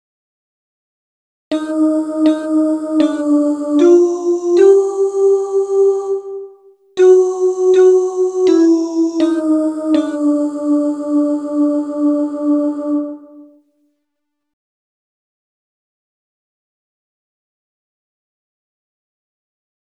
Key written in: B♭ Major
How many parts: 4
Type: Barbershop
Each recording below is single part only.